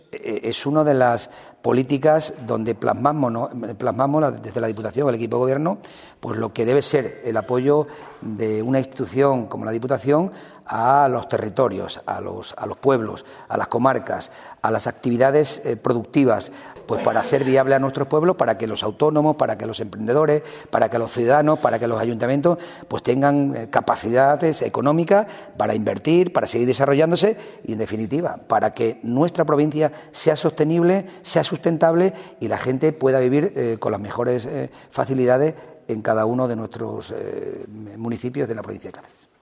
CORTES DE VOZ
Miguel Ángel Morales_Presidente de Diputación de Cáceres_GAL